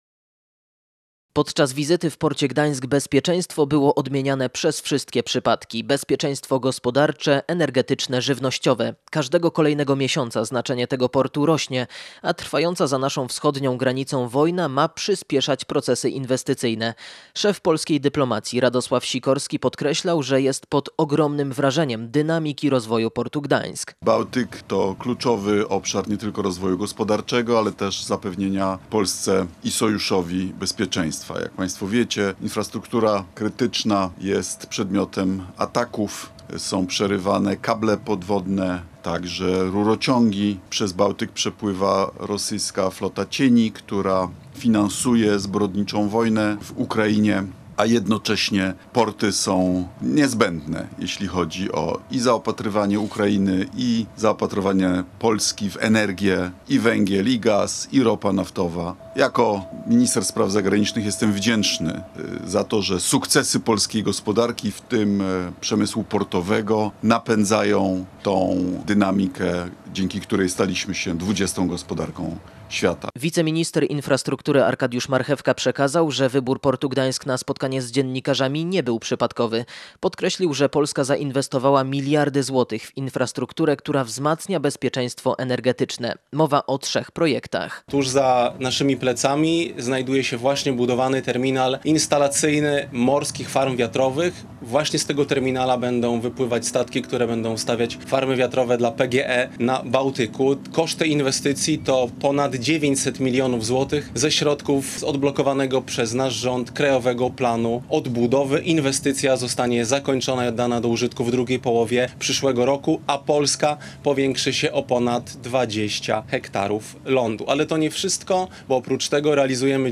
Morze Bałtyckie to kluczowy obszar dla rozwoju gospodarczego i zapewnienia Polsce oraz NATO bezpieczeństwa – podkreślił na konferencji prasowej w Gdańsku wicepremier i minister spraw zagranicznych Radosław Sikorski.